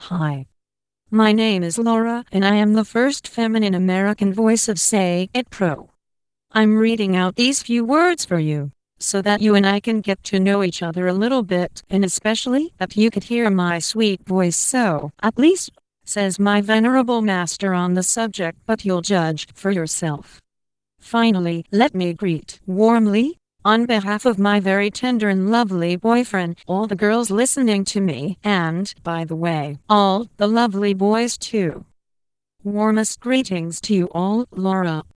Texte de démonstration lu par Laura, première voix féminine américaine de LogiSys SayItPro (version 1.70)